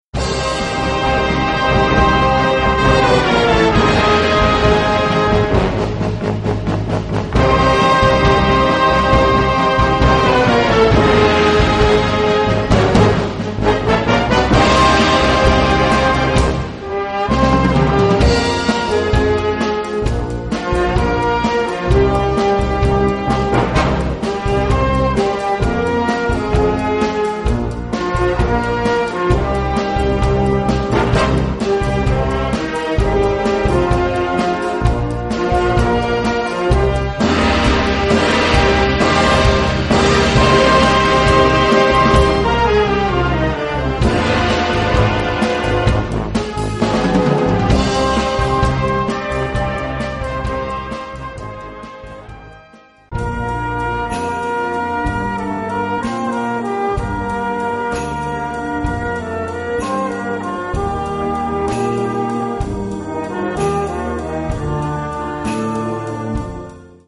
Gattung: Potpourri
Besetzung: Blasorchester